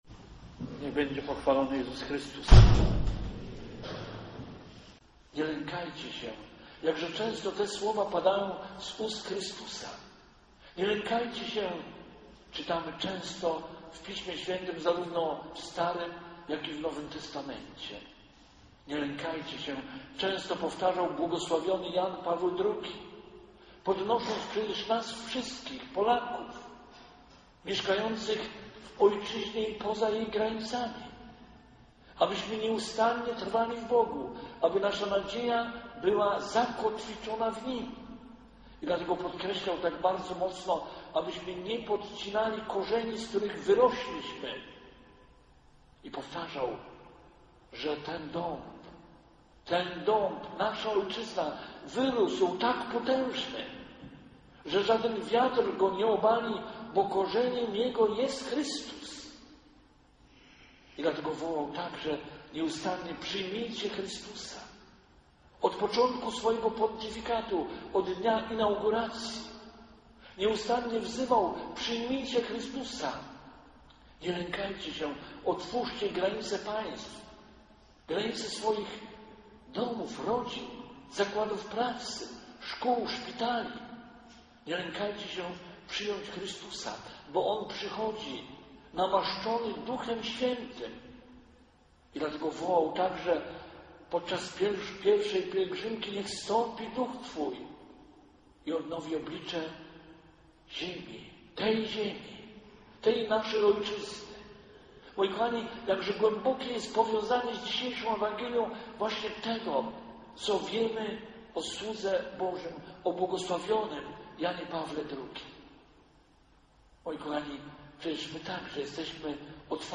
W niedzielę natomiast głosił Słowo Boże na wszystkich Mszach św., dzieląc się z parafianami osobistymi refleksjami i doświadczeniami ze swojej ponad dwudziestoletniej pracy misyjnej na Czarnym Lądzie.